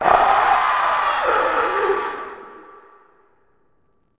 c_hunter_bat1.wav